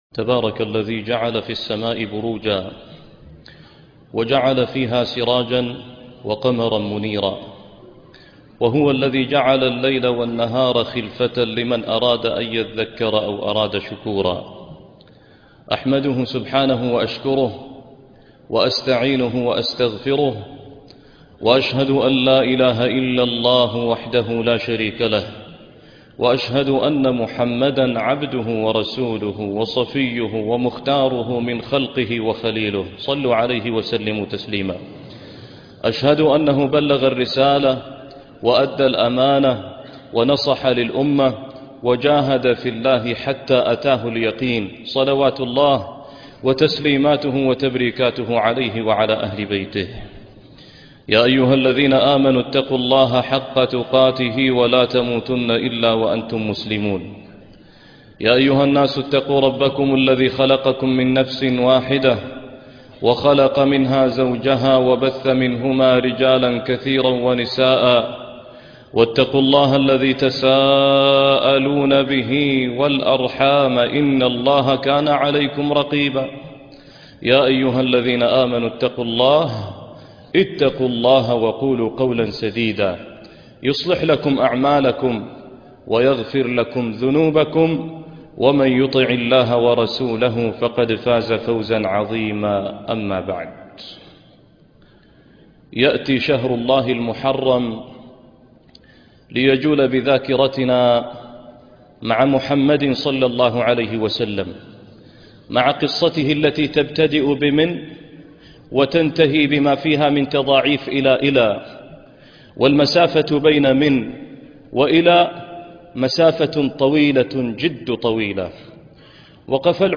قبس من نور الهجرة - خطبة الجمعة